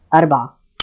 speech
speech-commands